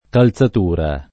calzatura [ kal Z at 2 ra ] s. f.